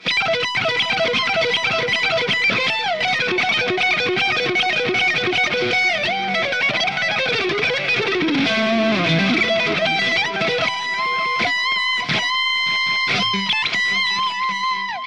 Lead
RAW AUDIO CLIPS ONLY, NO POST-PROCESSING EFFECTS